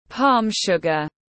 Đường thốt nốt tiếng anh gọi là palm sugar, phiên âm tiếng anh đọc là /pɑːm ˌʃʊɡ.ər/